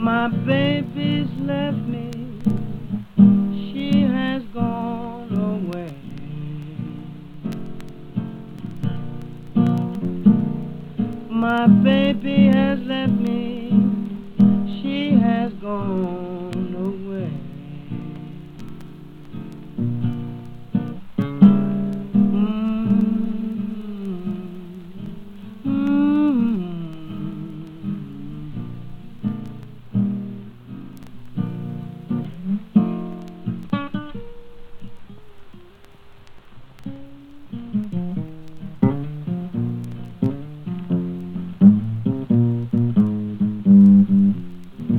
Jazz, Blues　USA　12inchレコード　33rpm　Mono